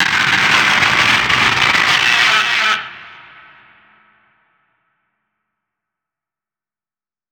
File:Jupiter Roar.wav
Jupiter_Roar.wav